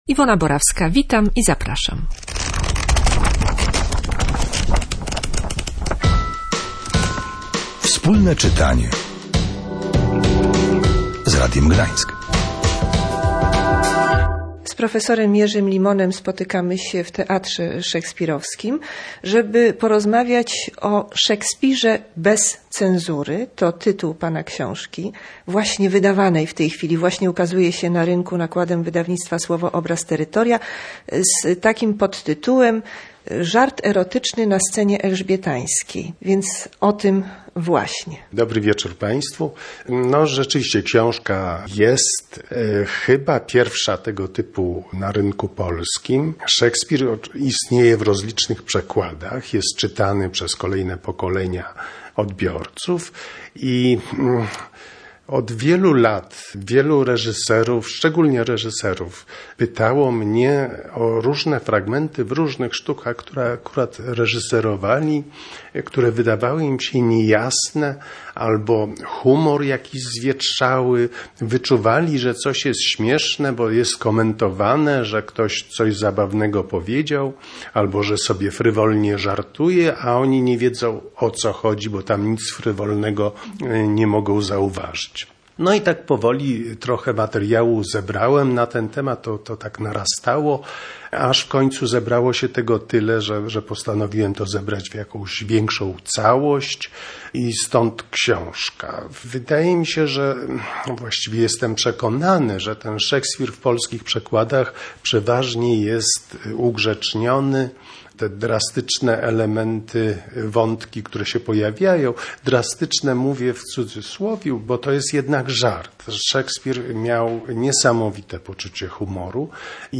W dniu inauguracji 22 Festiwalu Szekspirowskiego i premiery nowej książki profesora Jerzego Limona rozmawiamy o niej z autorem.